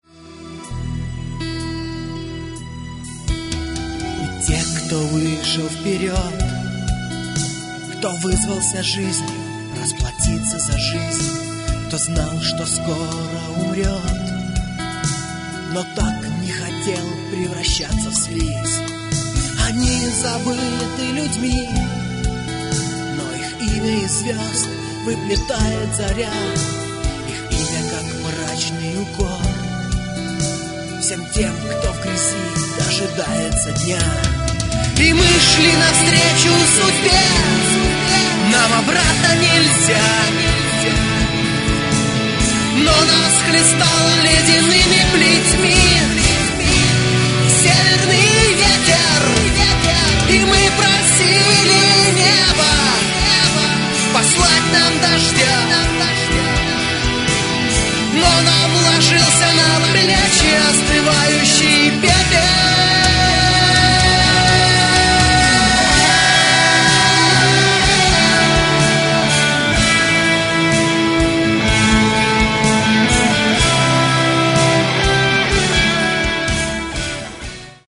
Гитары, клавиши, перкуссия, вокал
фрагмент (456 k) - mono, 48 kbps, 44 kHz